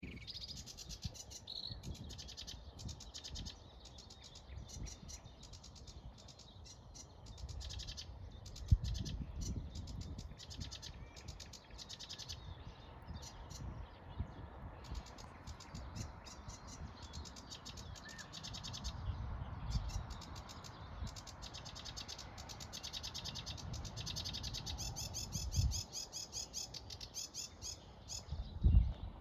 Birds -> Warblers ->
Sedge Warbler, Acrocephalus schoenobaenus
StatusSinging male in breeding season